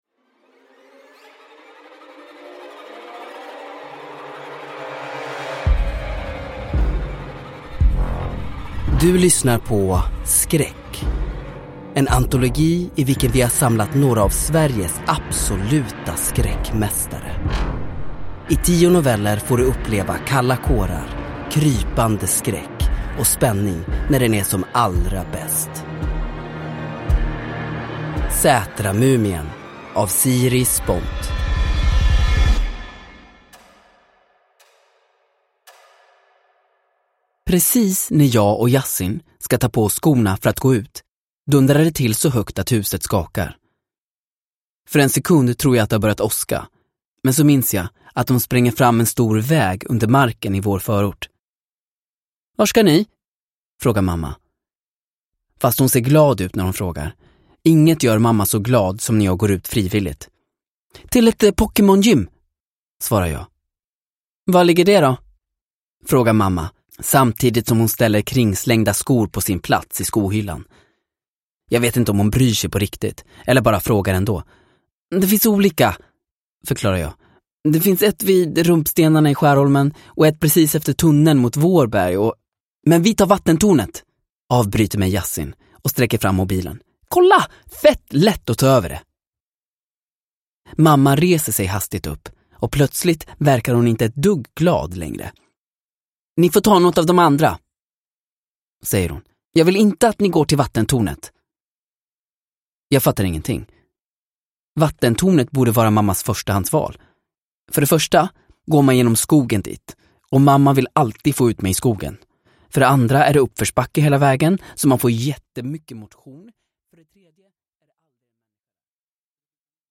Skräck - Sätramumien – Ljudbok – Laddas ner